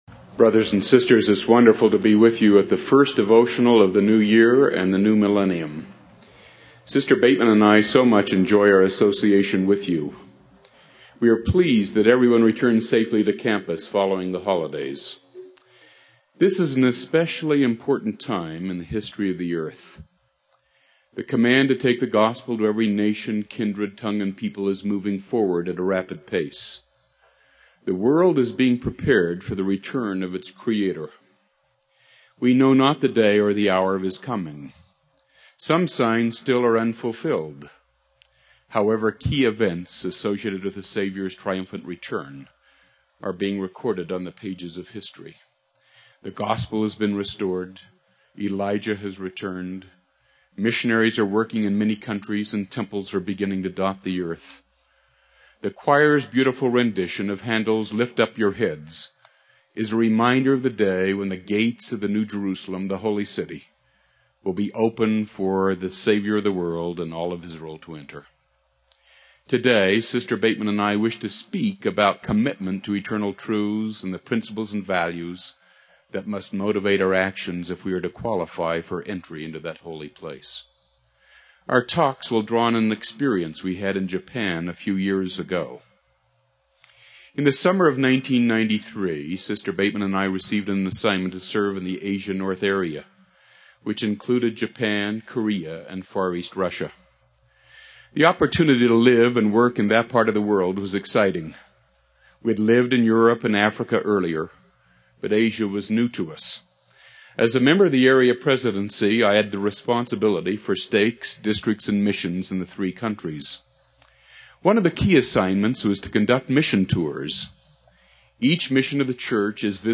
Devotional